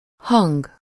Ääntäminen
IPA: /sɔ̃/